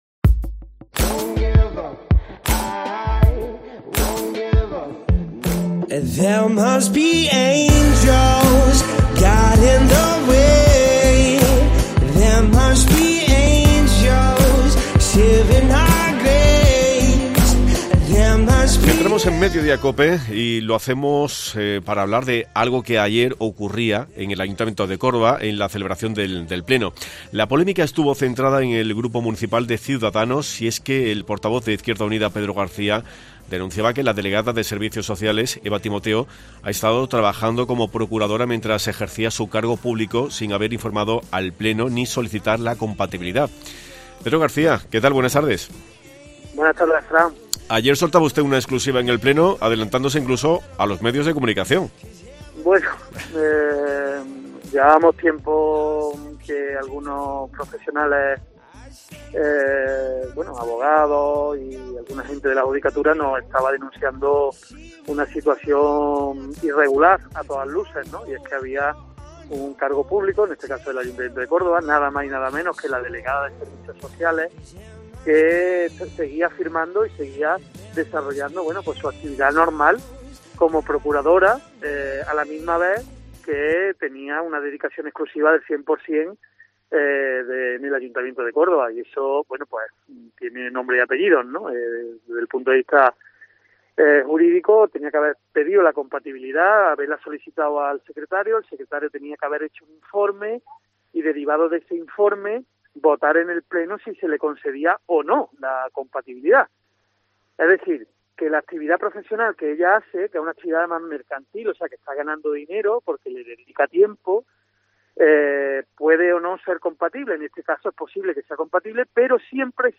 El portavoz de Izquierda Unida, Pedro García, ha pasado hoy por los micrófonos de COPE, donde ha solicitado de nuevo al alcalde que cese de inmediato a la concejal de Servicios Sociales, Eva Timoteo, por no informar al pleno de estar ejerciendo una actividad mercantil cuando cuenta con dedicación exclusiva a su cargo como delegada.